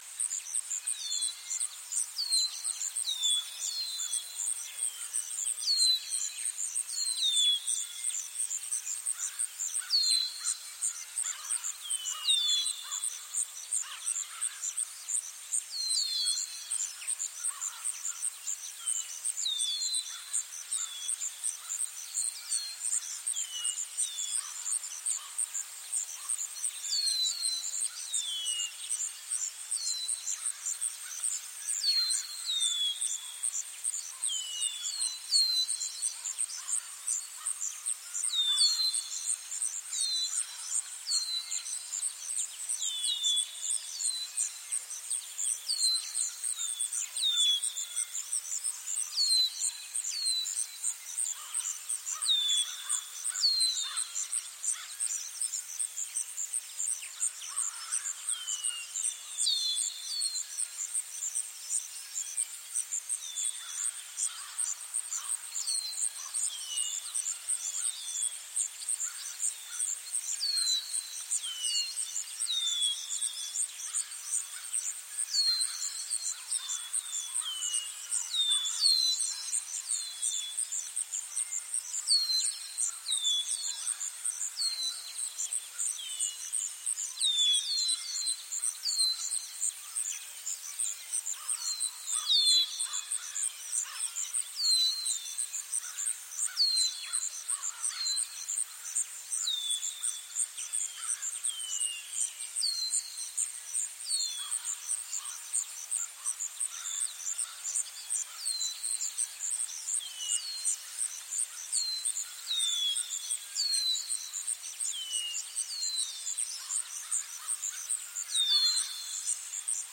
Здесь собраны натуральные записи солнечных дней: легкий ветер в кронах деревьев, стрекотание кузнечиков, плеск воды и другие уютные звучания.
Атмосферный звук хорошей погоды